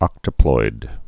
(ŏktə-ploid)